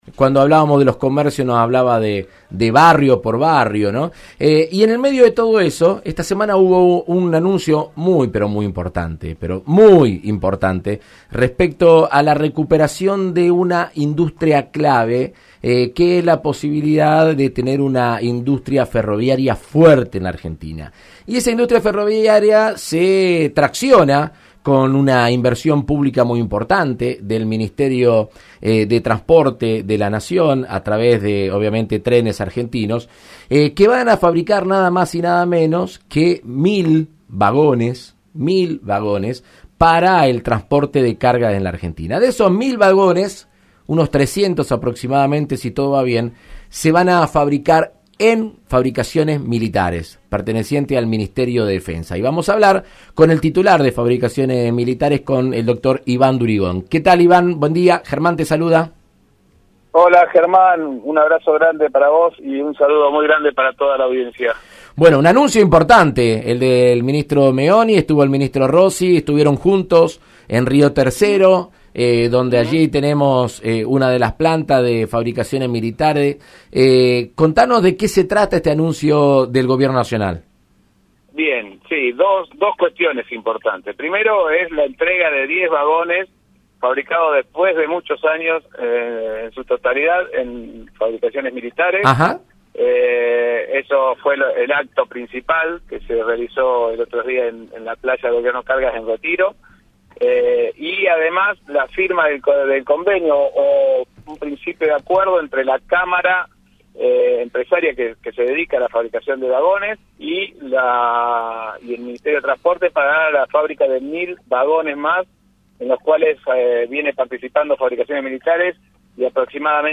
De la mano de Trenes Argentinos se está recuperando la industria ferroviaria y Fabricaciones Militares tiene un papel importante en la construcción de vagones. El diputado nacional Germán Martínez dialogó en Argentina Unida Contra el Coronavirus con Iván Durigón, titular de la empresa dependiente del Ministerio de Defensa.